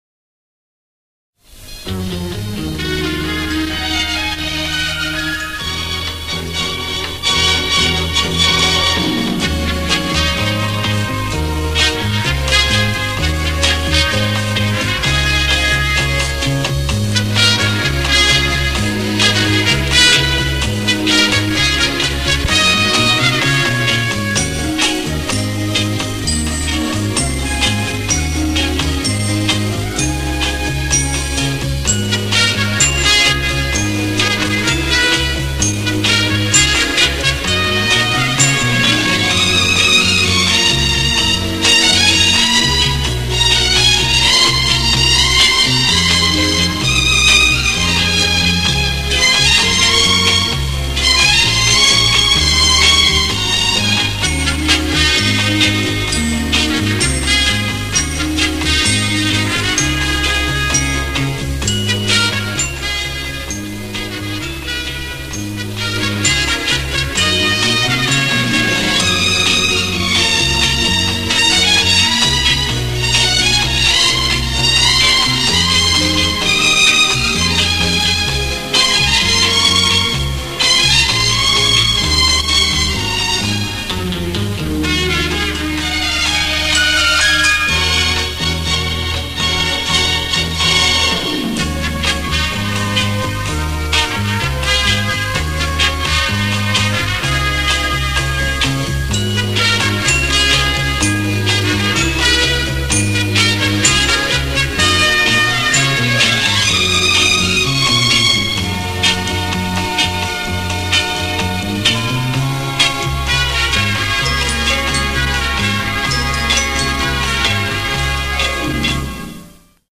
А почему это не может быть любым ГДР-овским оркестром?